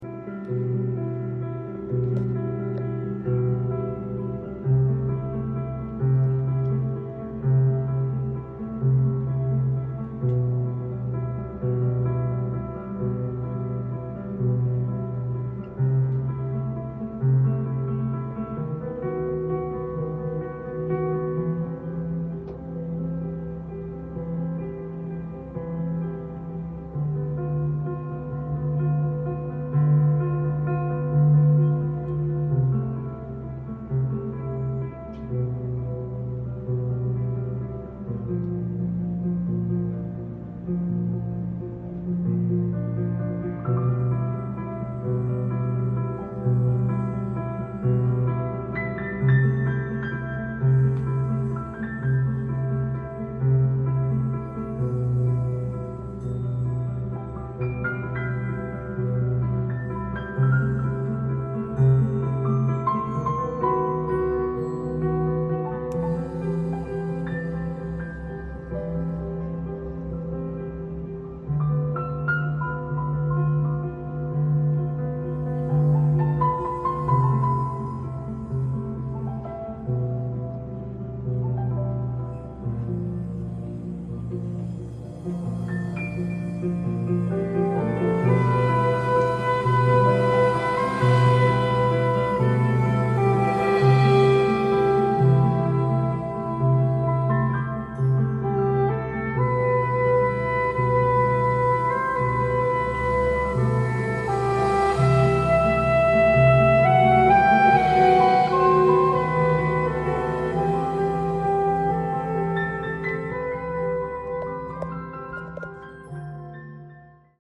店頭で録音した音源の為、多少の外部音や音質の悪さはございますが、サンプルとしてご視聴ください。
ECMらしい内省的なサウンドと各楽器のレイヤリングが美しく重なり合う、アンビエント色強いコンテンポラリー・ジャズ。